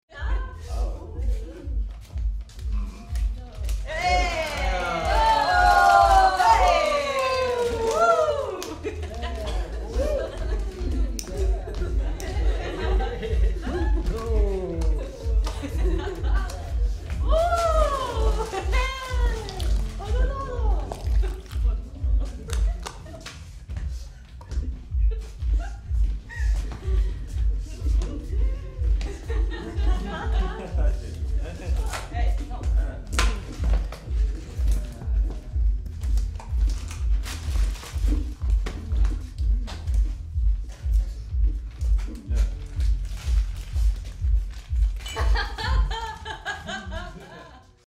2. Хаус пати. Вечеринка студентов в съемной квартире